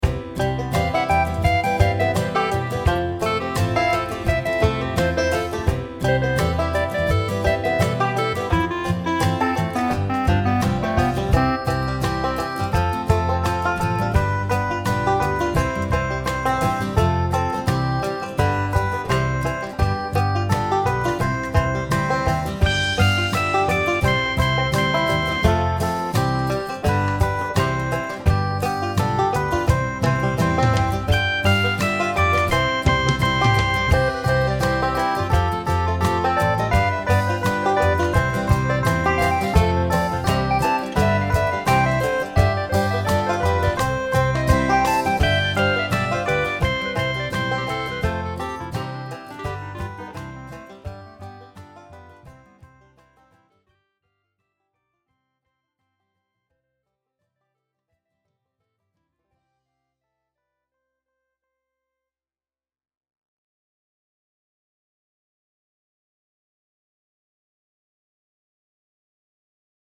Maza dziesmiņa Play-along.